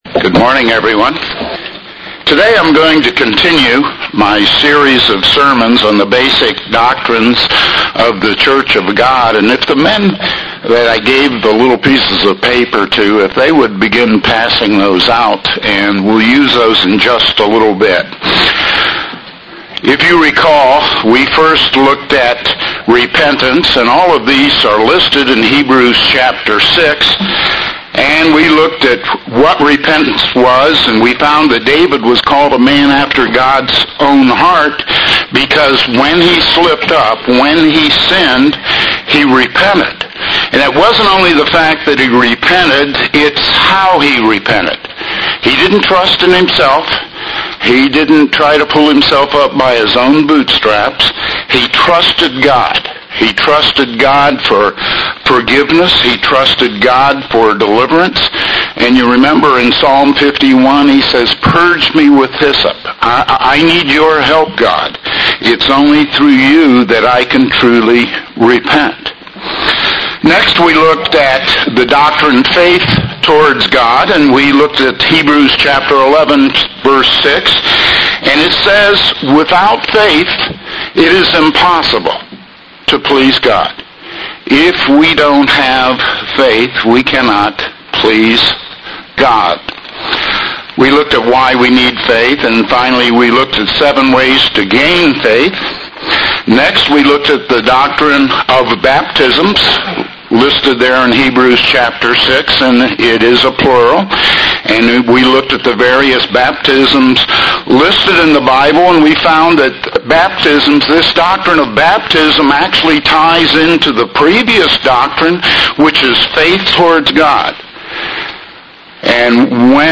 Given in Hickory, NC
UCG Sermon Studying the bible?